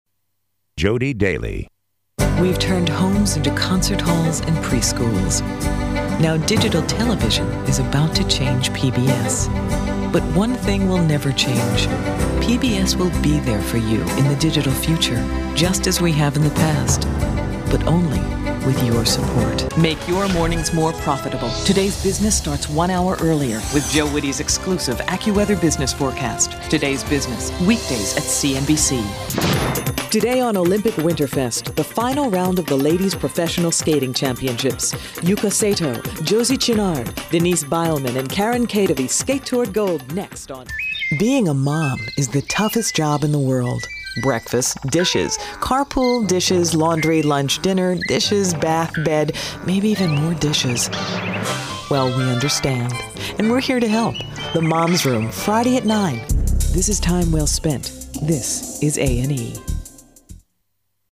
Female VOs